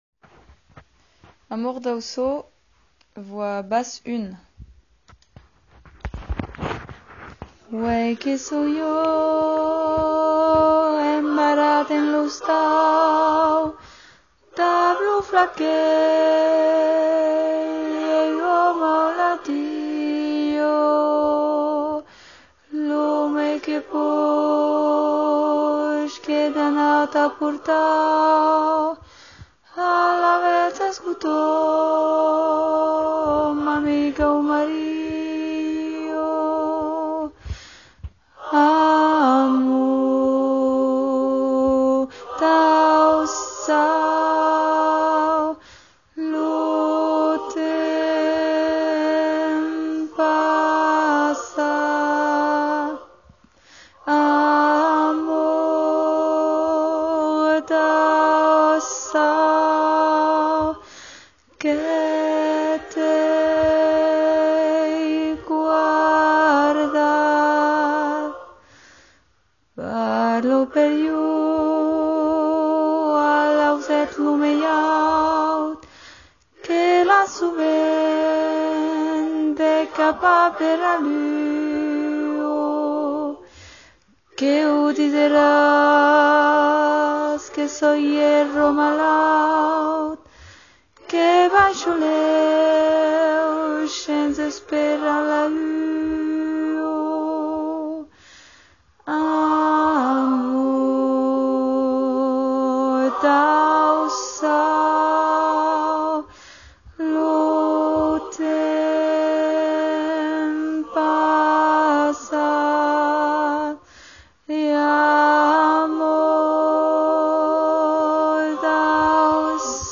Voix séparées (4 voix mixtes)
basse 1
ba38b-amor-d-aussau-basse1.mp3